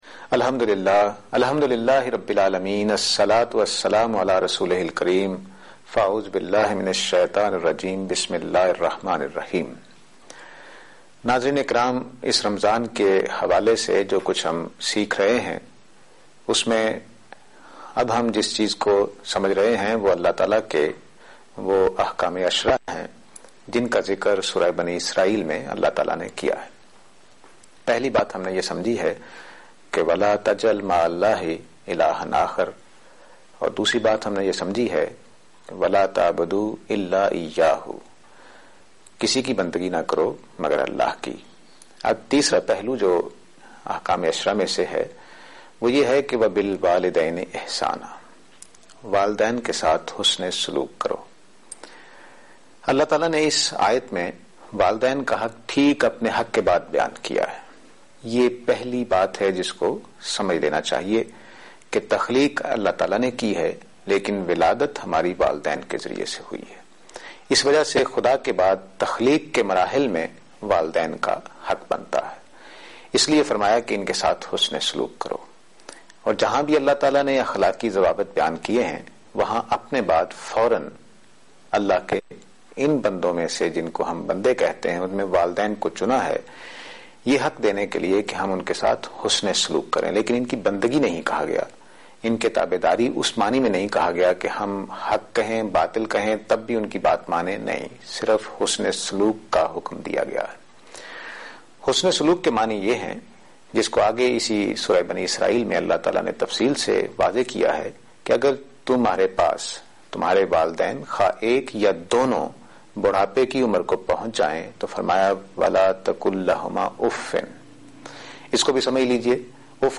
Program Tazkiya-e-Ikhlaq on Aaj Tv.